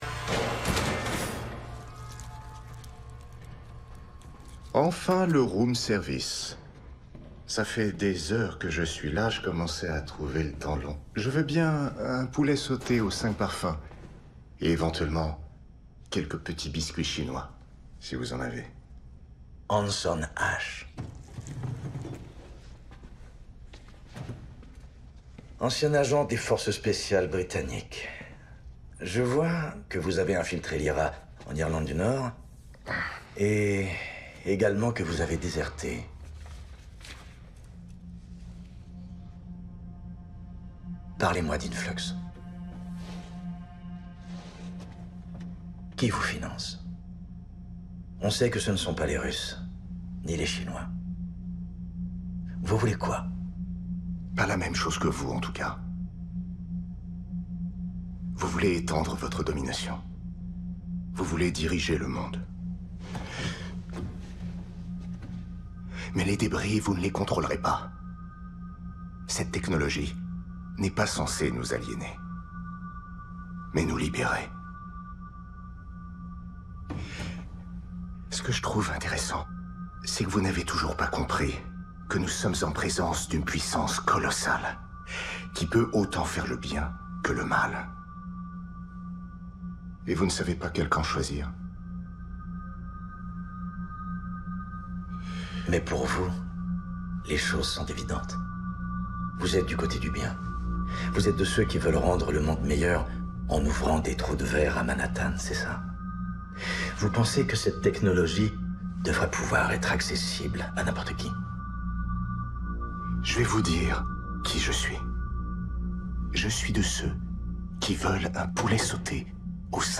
Calme, déterminé et pervers.
Enregistré chez Deluxe.